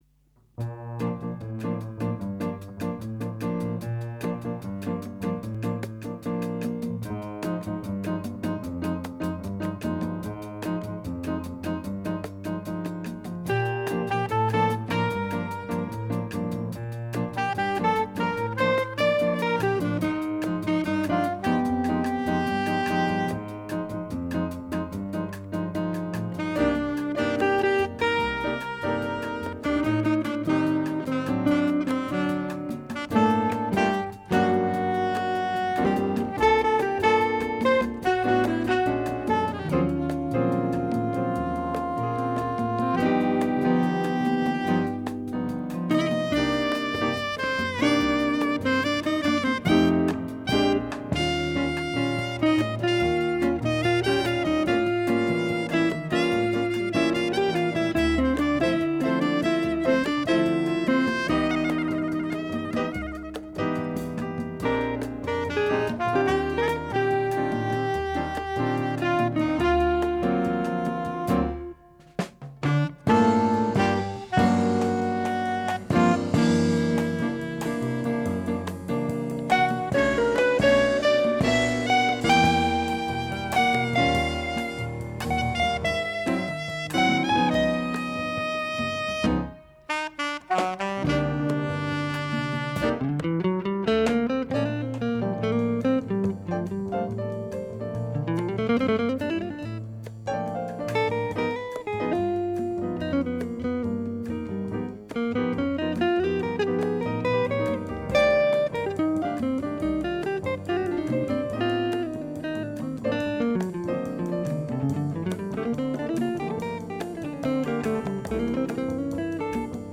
flûte & saxophone alto
guitare
piano
bass
conga
drums) Studio RSR – Genève